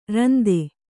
♪ rande